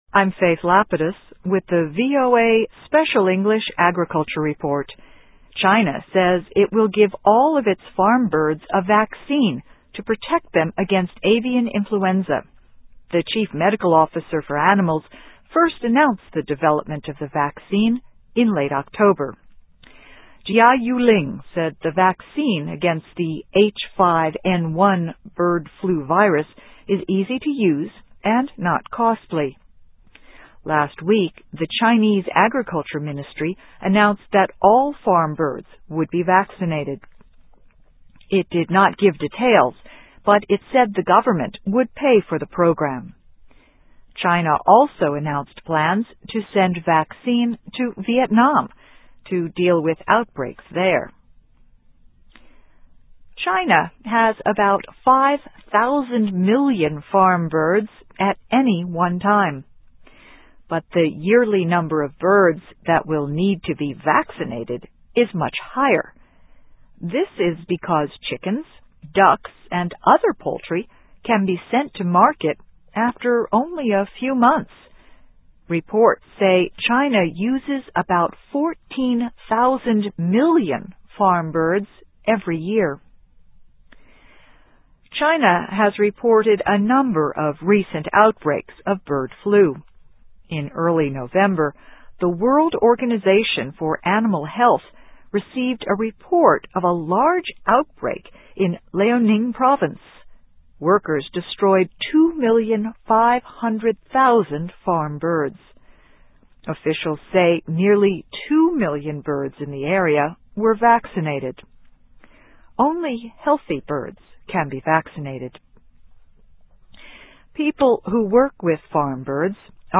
Voice of America Special English